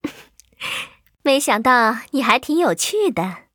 文件 文件历史 文件用途 全域文件用途 Erze_fw_03.ogg （Ogg Vorbis声音文件，长度0.0秒，0 bps，文件大小：44 KB） 源地址:游戏语音 文件历史 点击某个日期/时间查看对应时刻的文件。